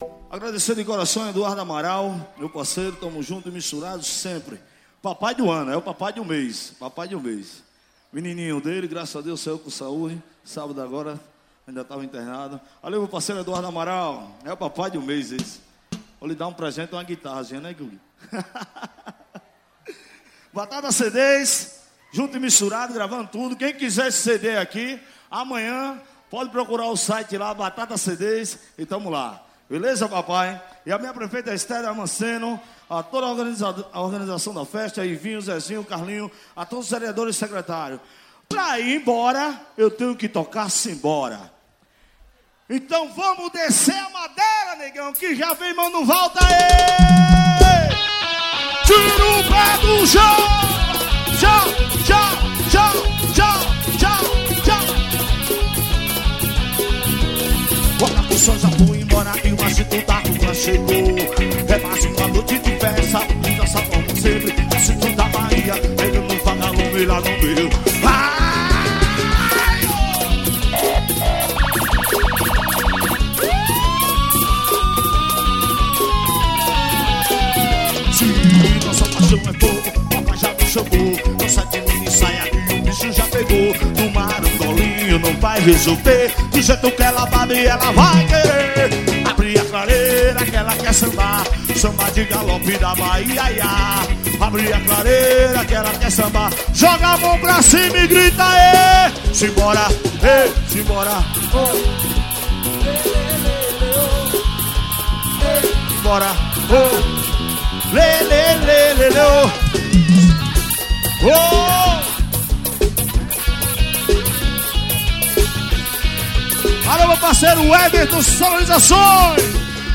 Composição: AXÉ.